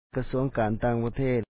kasúaŋ kaan tāaŋ-pathèet Ministry of Foreign Affairs